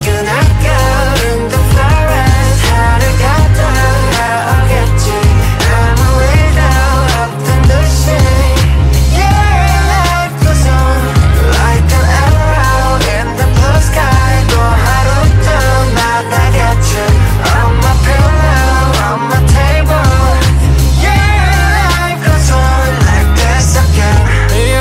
Thể loại nhạc chuông: Nhạc hàn quốc